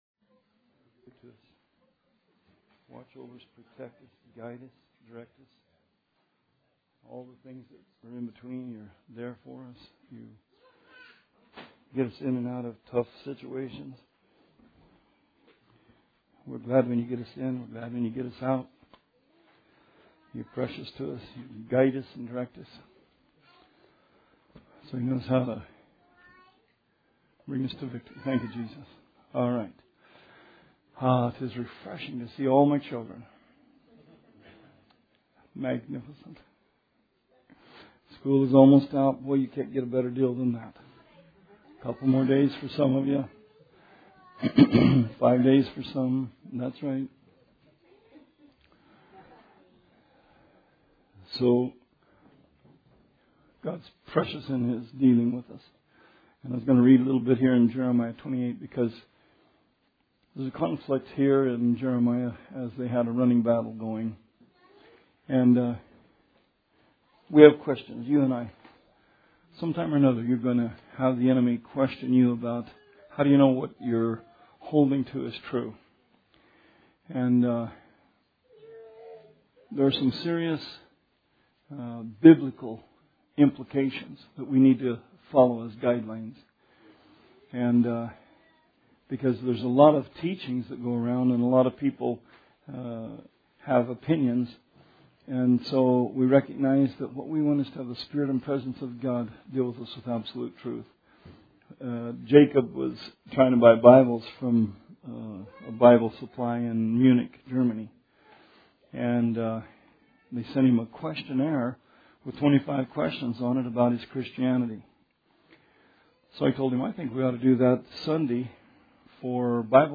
Bible Study 5/18/16